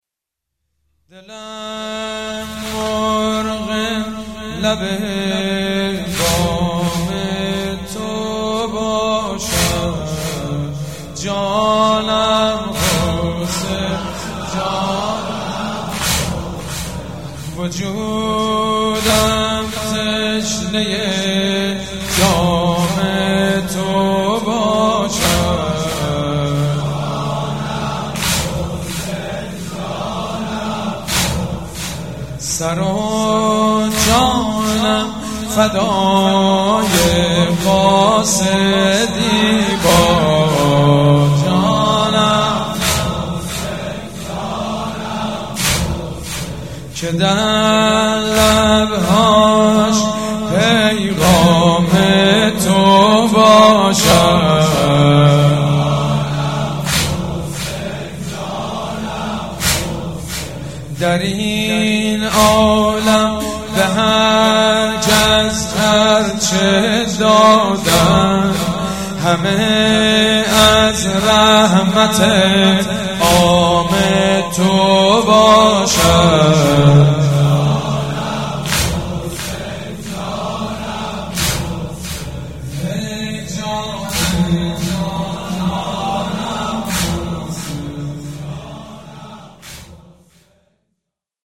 مداح
مراسم عزاداری شب عاشورا